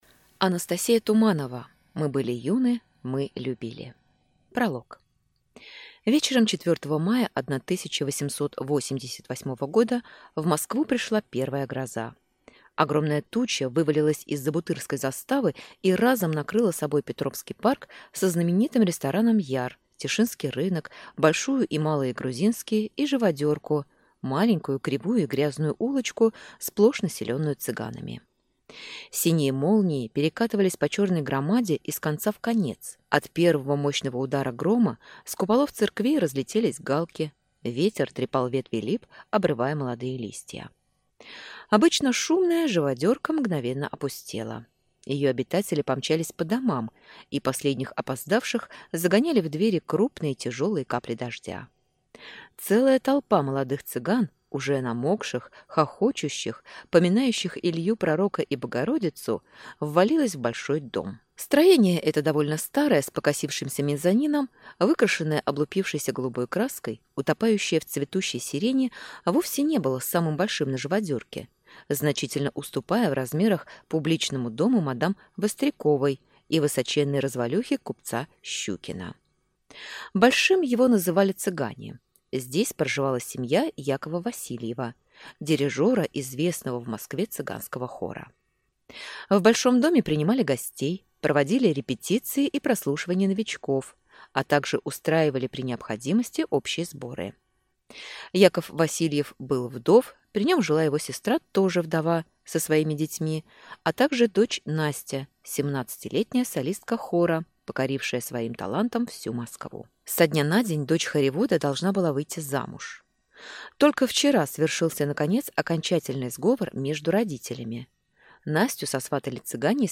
Аудиокнига Мы были юны, мы любили | Библиотека аудиокниг